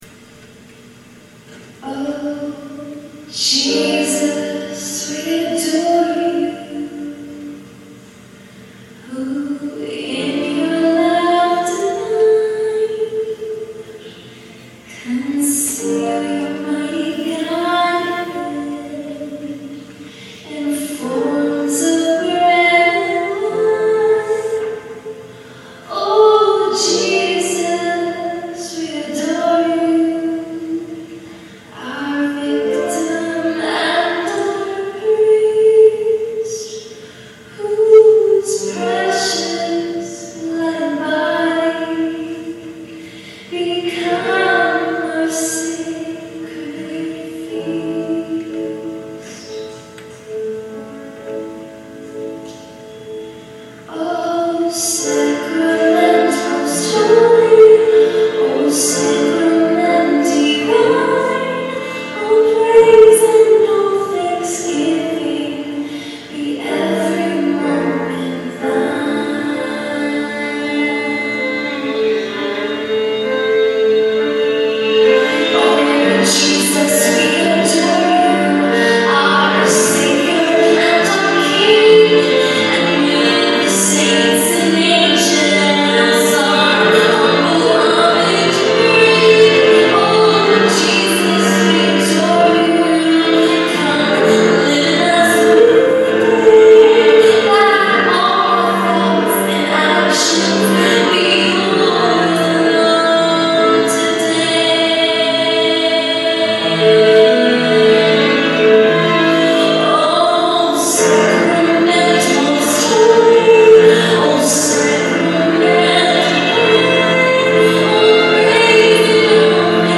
at St Joseph Catholic Church